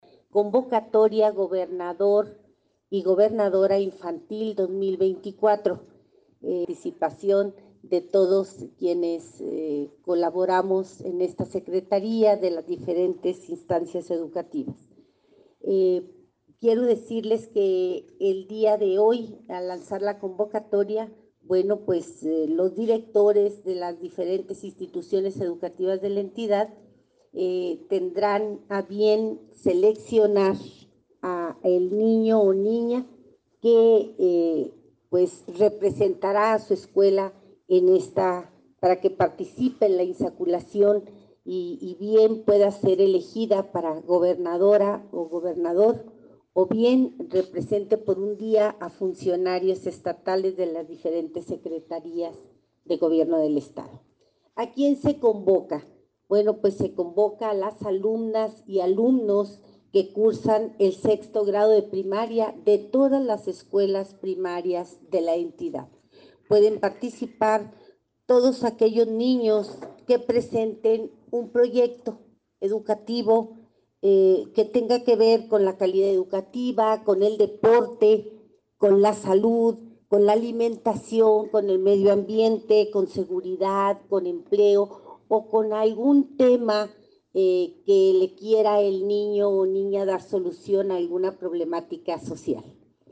AUDIO: SANDRA GUTÍERREZ, TITULAR DE LA SECRETARÍA DE EDUCACIÓN Y DEPORTE (SEyD)